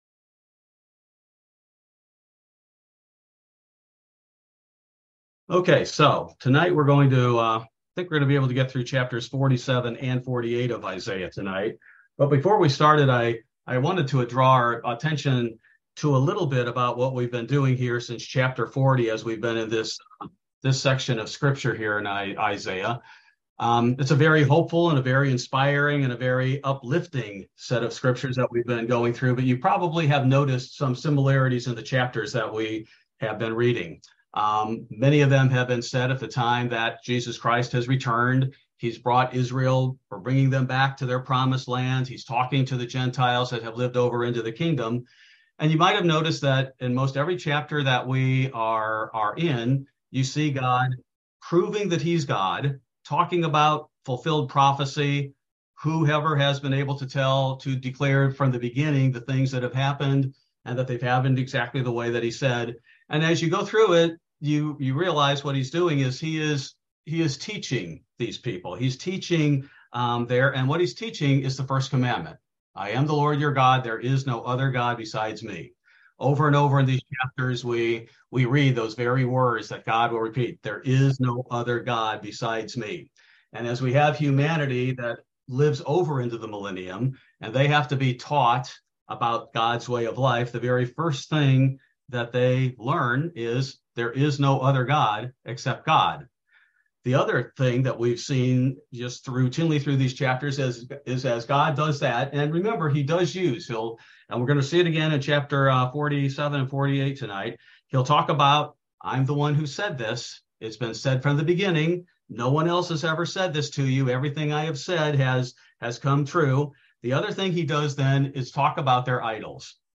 This verse by verse Bible Study focuses primarily on Isaiah 47-48: Three Commandments and "New" Prophecies